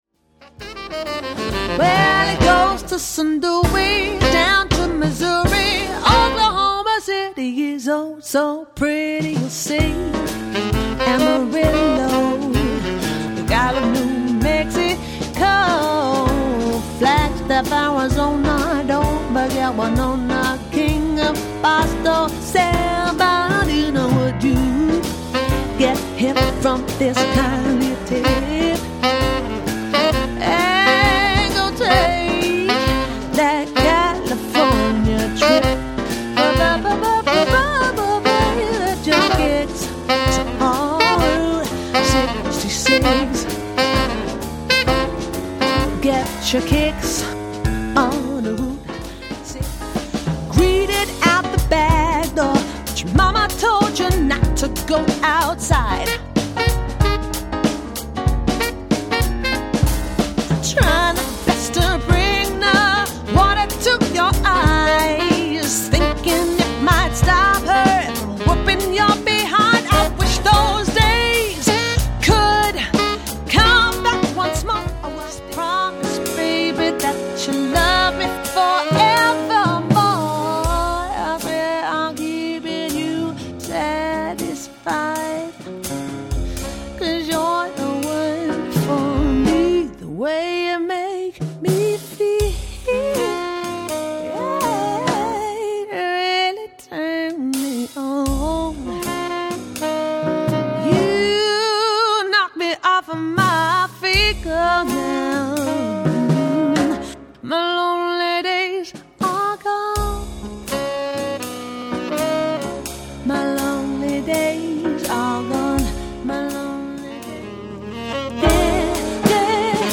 Vocals / Piano